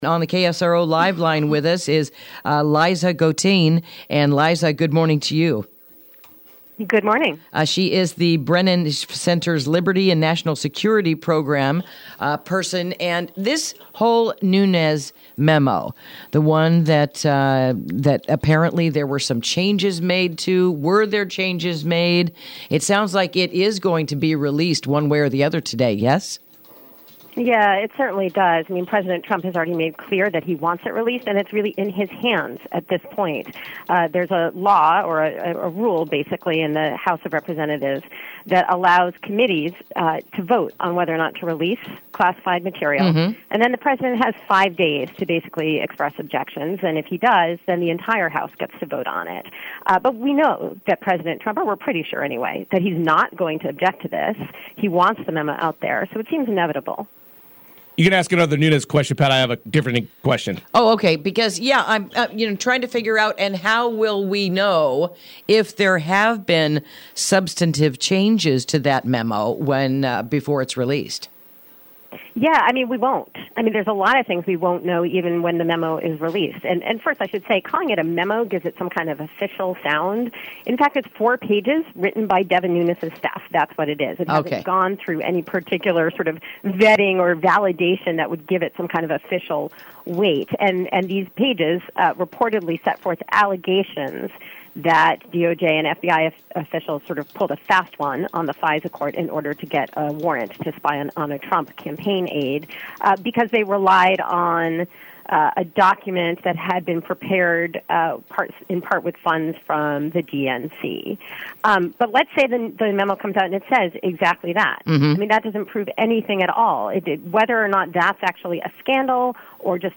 Interview: Implication of the Newly Released Nunes Memo